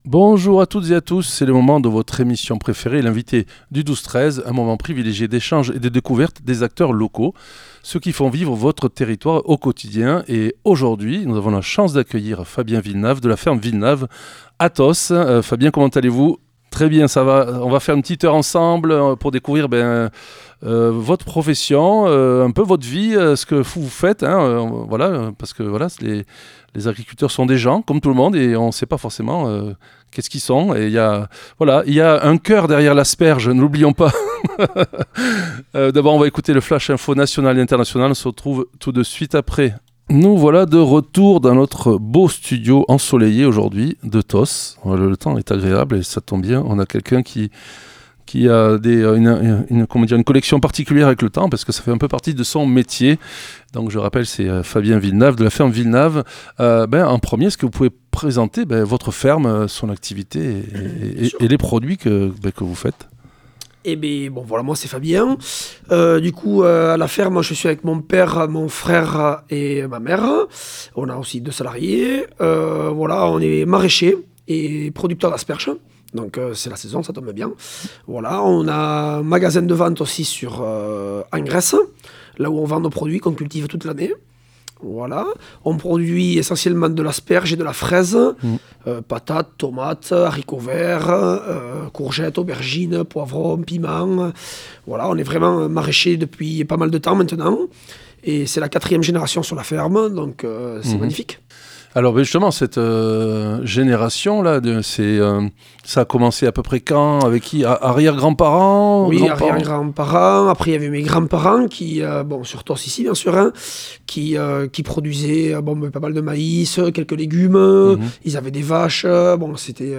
L'invité(e) du 12-13 de Soustons recevait aujourd'hui